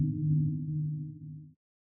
sonarTailAirFar1_Directional.ogg